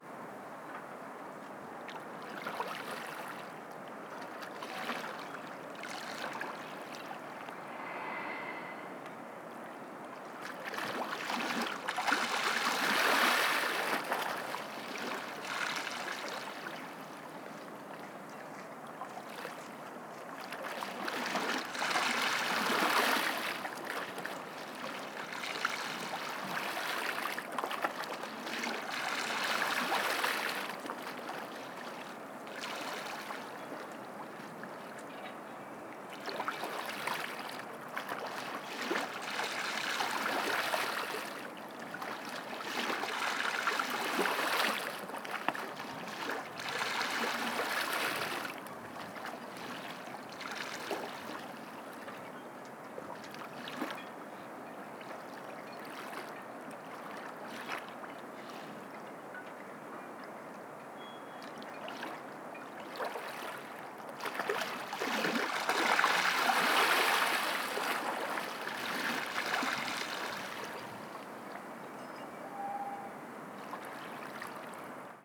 Splash
A very sunny and non-windy morning in Ramsgate harbour today.